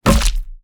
wood_bat_finisher_05.wav